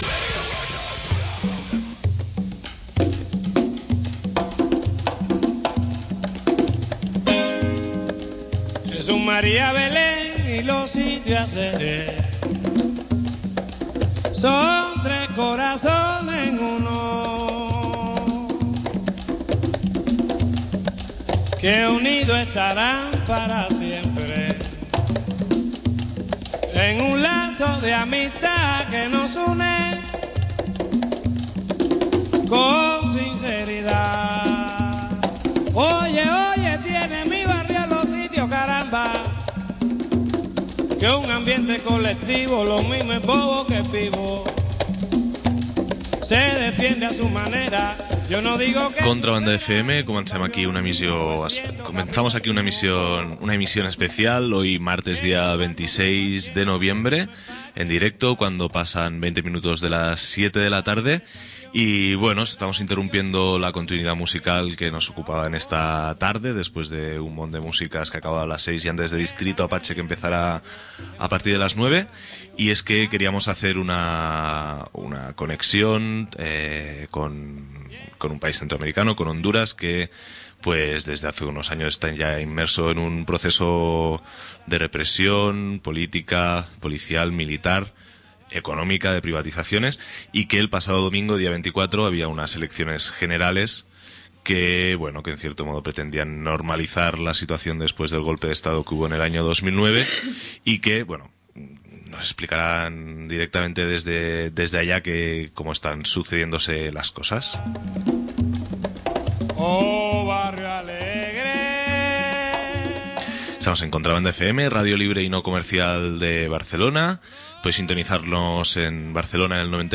Programa especial de 26 de noviembre de 2013 dedicado a el proceso electoral fraudulento en Honduras, con una conexión en directo desde allá.